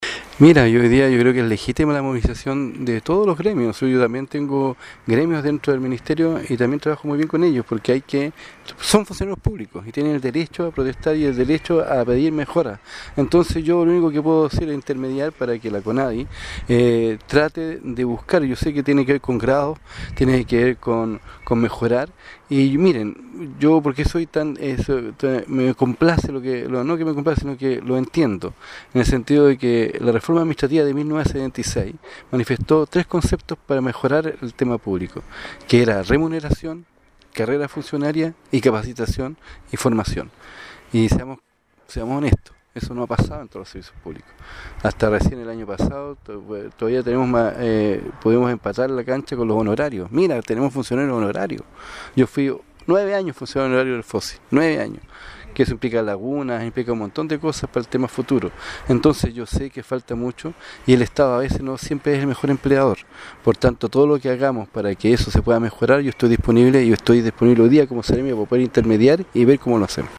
Consultado sobre esta situación, el Seremi de Desarrollo Social, Enzo Jaramillo, reconoció situaciones complejas en la administración pública, como también en la Conadi, y se ofreció para intermediar para mejorar las condiciones laborales de sus funcionarios